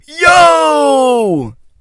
描述：一个花花公子说耶。
标签： 冷却 冷笑话男孩 酷男孩 傻瓜 是啊 帅哥 酷花花公子 纨绔子弟 高飞 花花公子 男孩
声道立体声